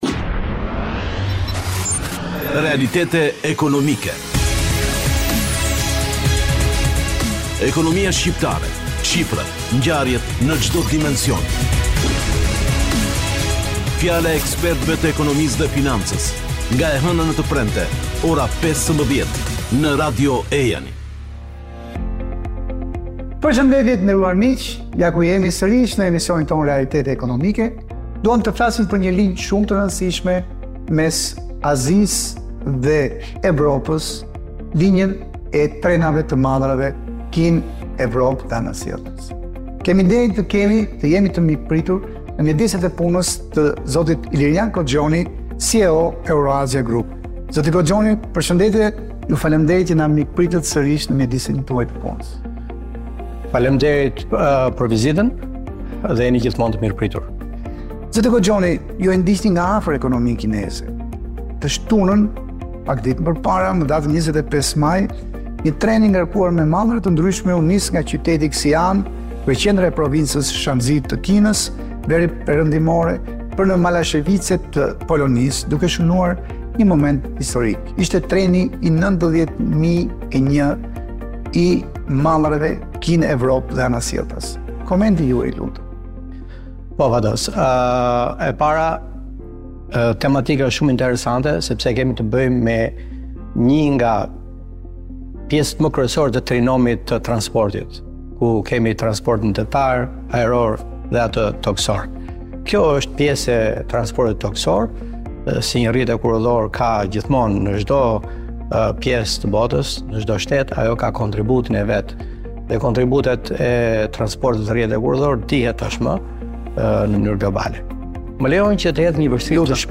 intervistë